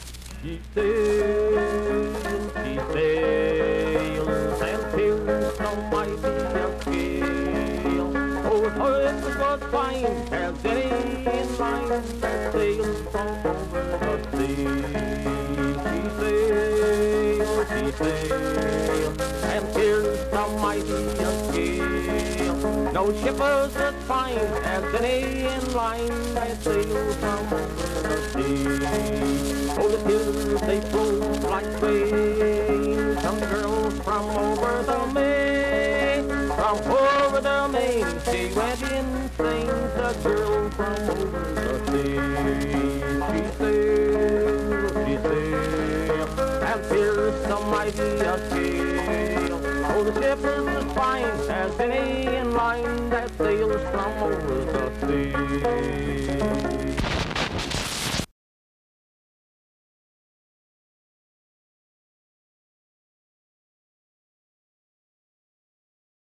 Vocal performance accompanied by banjo
Banjo, Voice (sung)
Wood County (W. Va.), Vienna (W. Va.)